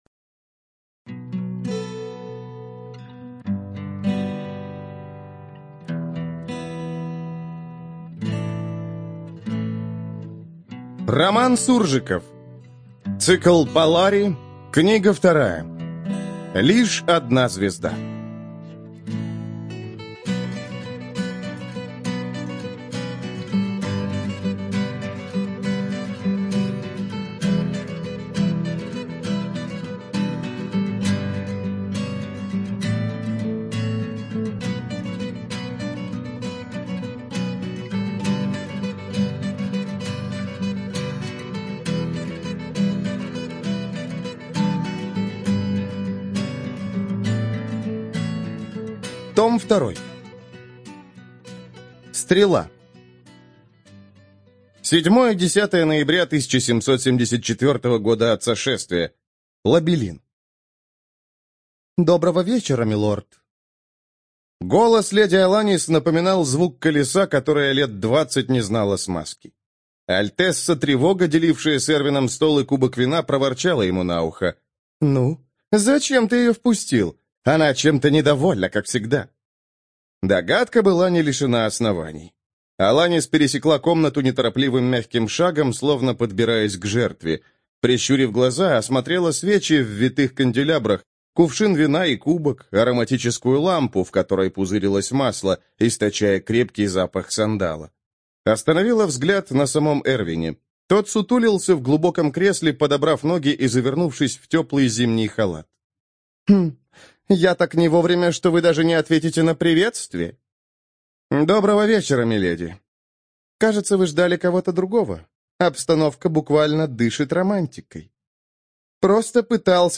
ЖанрФэнтези